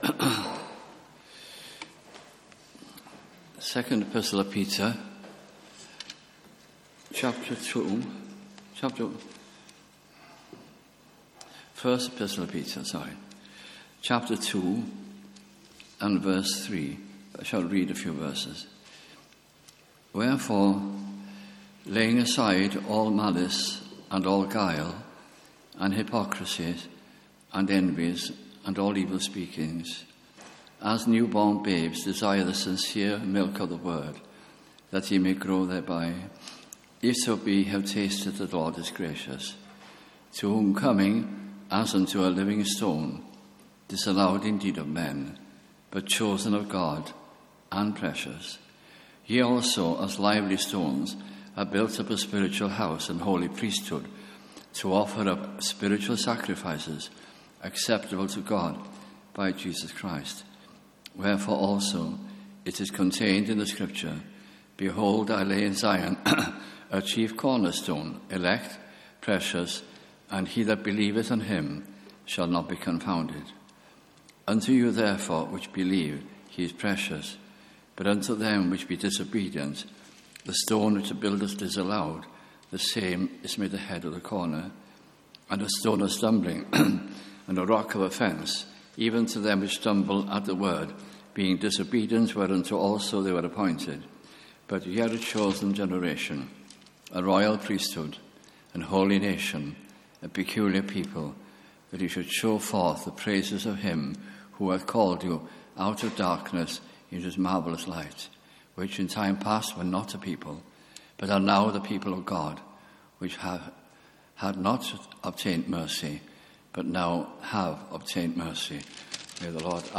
» 1 Peter » Bible Study Series 2010 - 2011 » at Tabernacle Cardiff